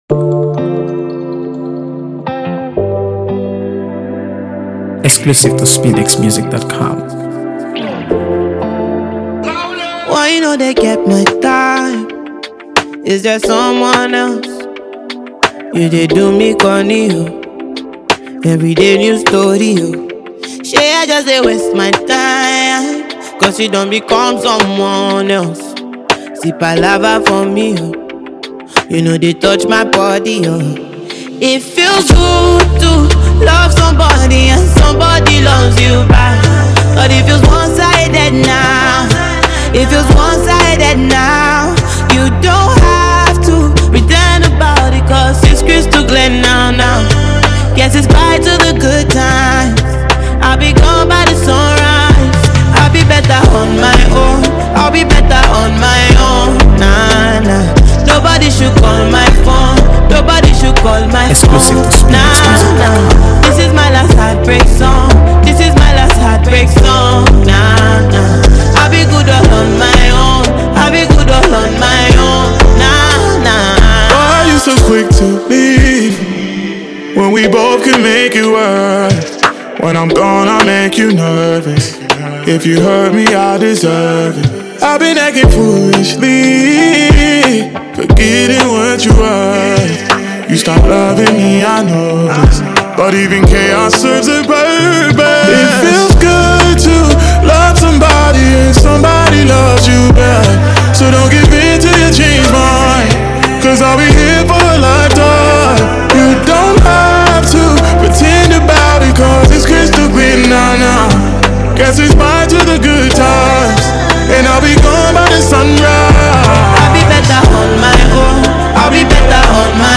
AfroBeats | AfroBeats songs
celebrated for her captivating voice and artistry